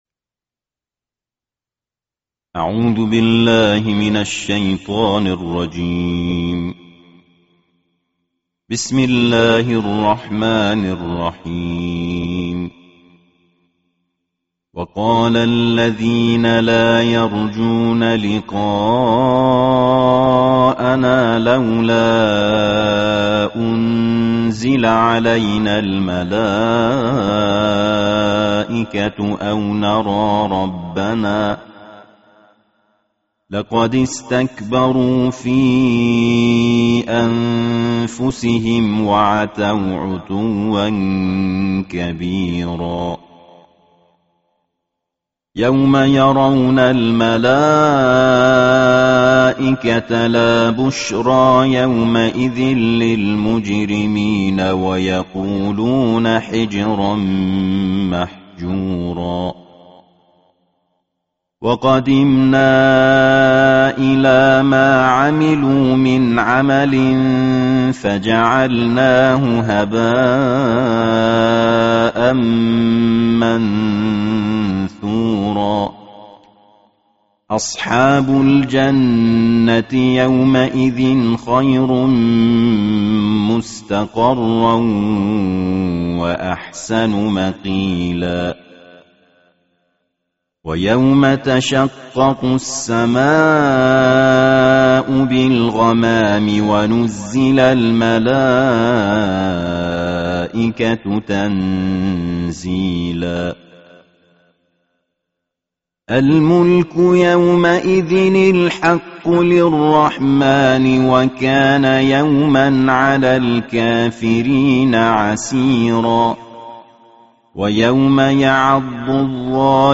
Bacaan Tartil Juzuk Kesembilanbelas Al-Quran